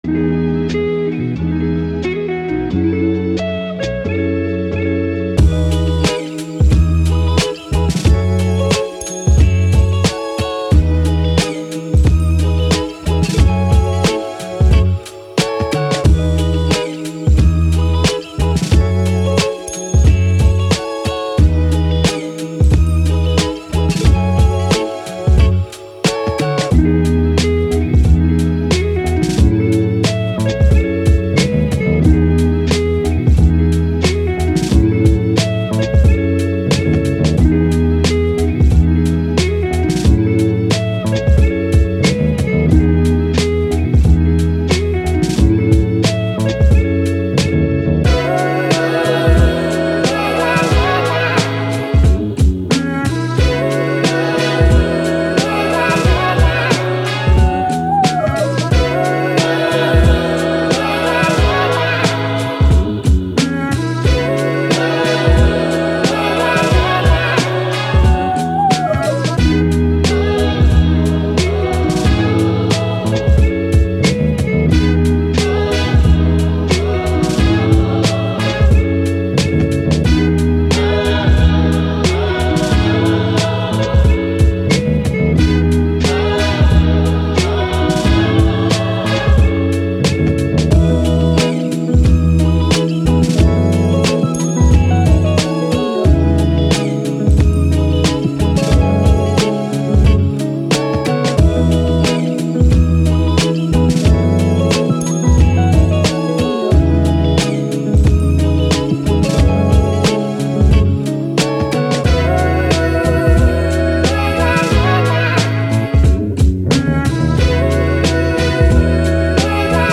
Soul, Hip Hop, Jazz, Uplifting, Positive, Vintage